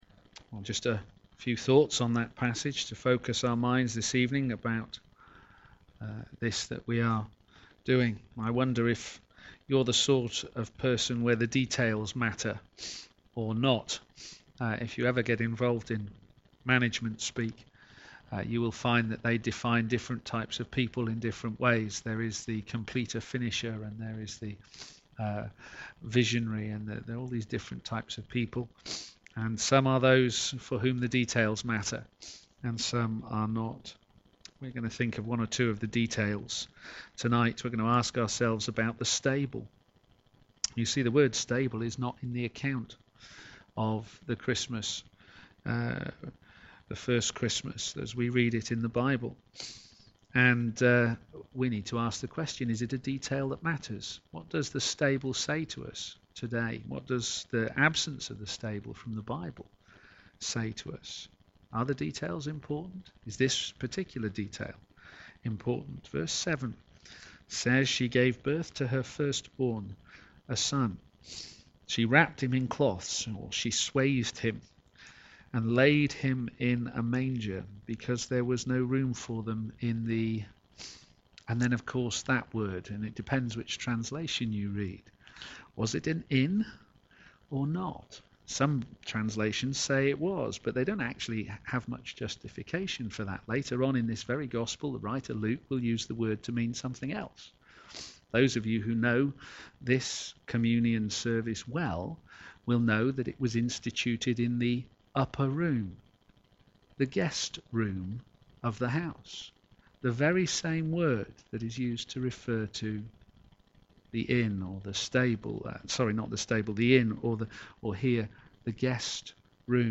Media Library Media for p.m. Service on Thu 24th Dec 2015 18:30 Speaker
19 Series: He Arrives! Theme: - In a manger! Sermon In the search box below, you can search for recordings of past sermons.